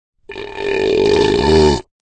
burp7.ogg